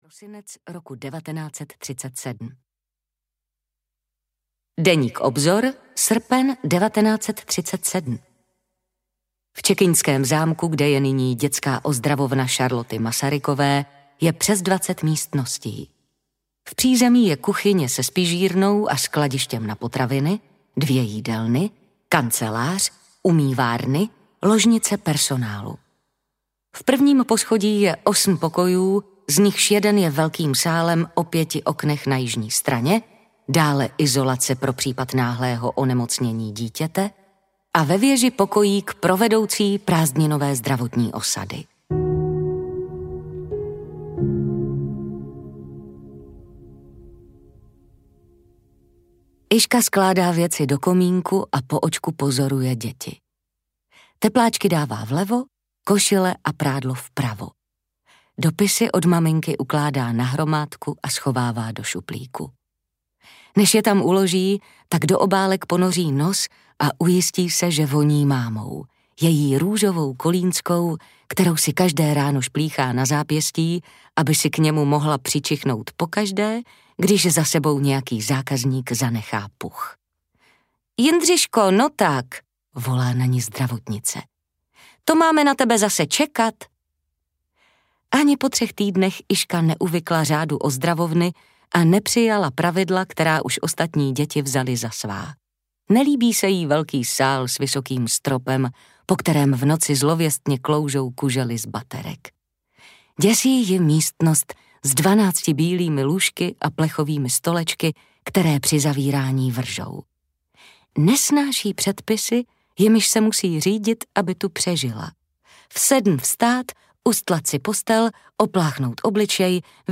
Když se trhá nebe audiokniha
Ukázka z knihy